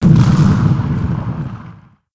explo2.wav